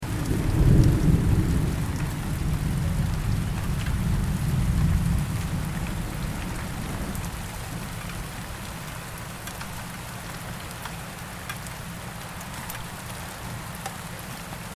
جلوه های صوتی
دانلود صدای رعدو برق 34 از ساعد نیوز با لینک مستقیم و کیفیت بالا